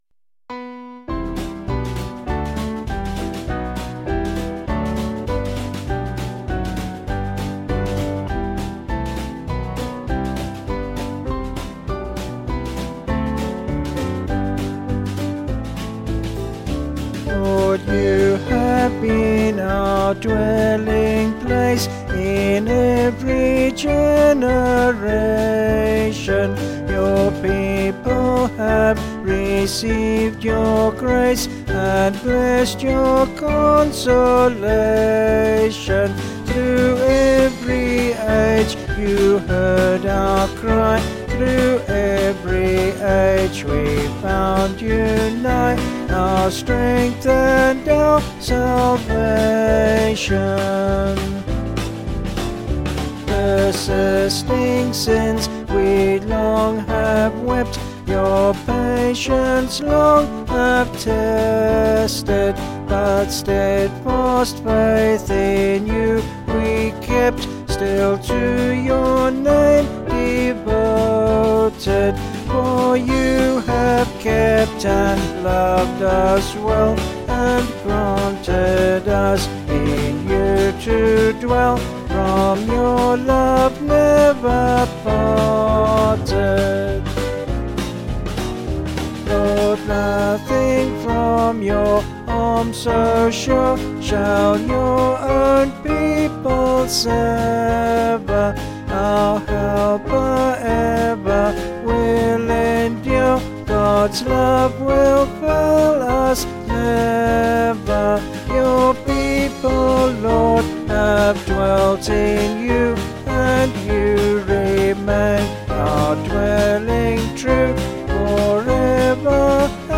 Vocals and Band   263.7kb Sung Lyrics